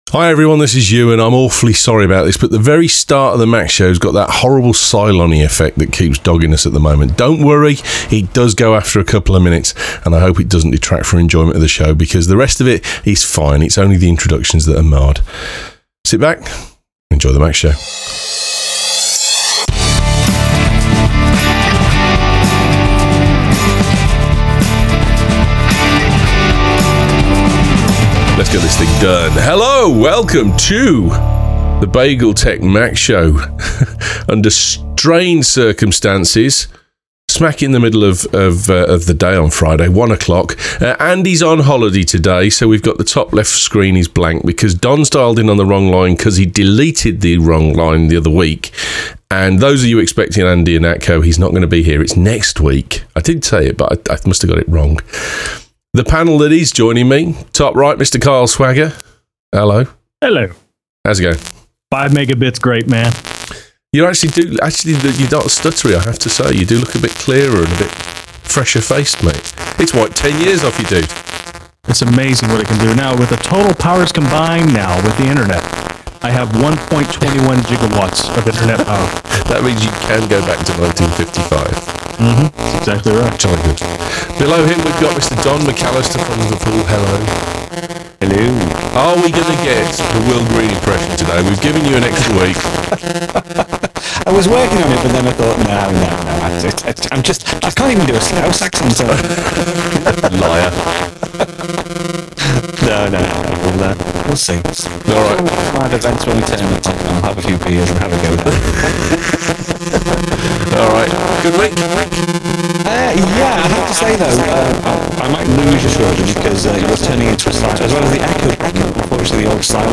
A Nightmare of Audio has meant that the first 4 minutes of the show are very distorted. My Apologies, but after that its just fine and dandy.